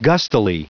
Prononciation du mot gustily en anglais (fichier audio)
Prononciation du mot : gustily